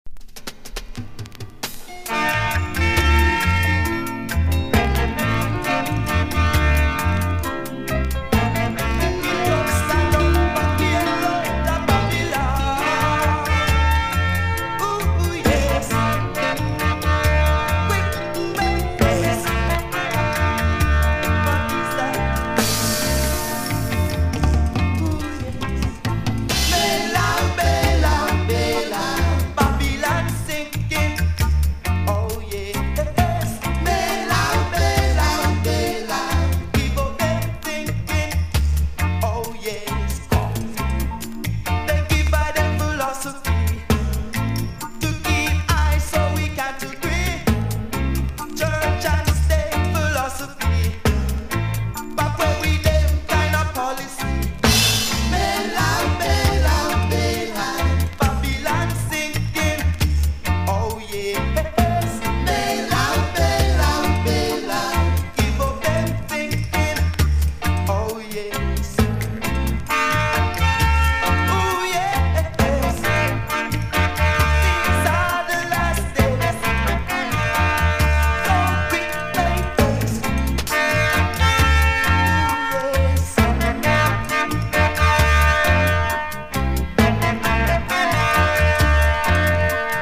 DUB / UK DUB / NEW ROOTS